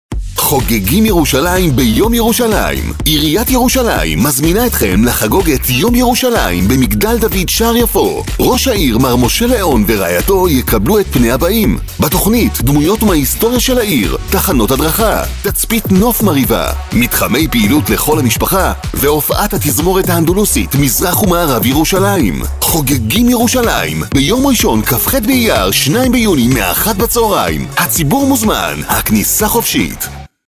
תשדירי רדיו לדוגמה